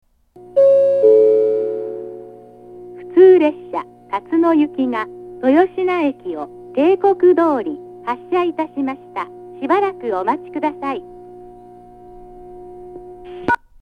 ２番線上り豊科駅発車案内放送 普通辰野行の放送です。
hitoichiba-2bannsenn-nobori-toyoshina-hassha.mp3